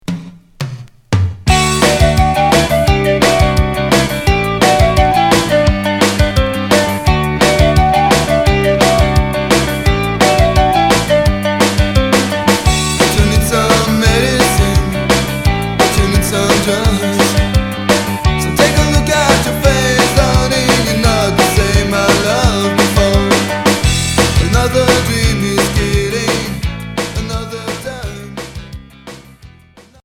Rock new wave Unique 45t retour à l'accueil